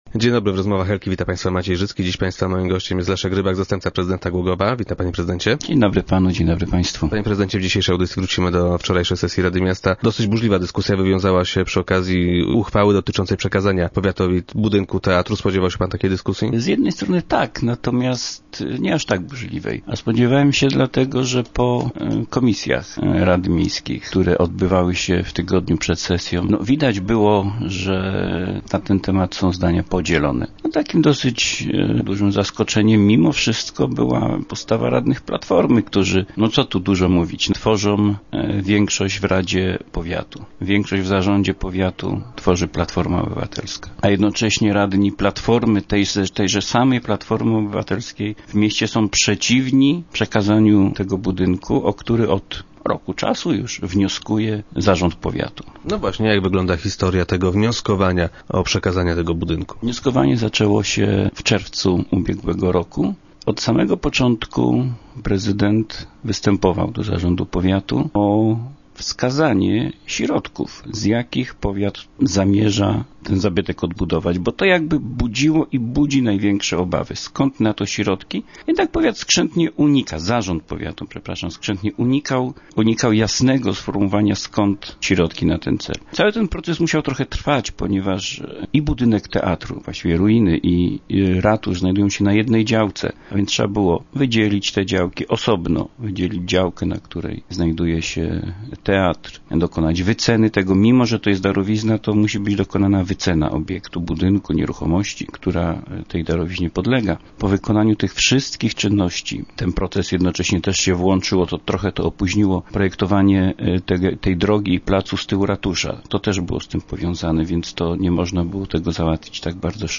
- Dziwią mnie takie działania opozycji - twierdzi Leszek Rybak, zastępca prezydenta i gość Rozmów Elki.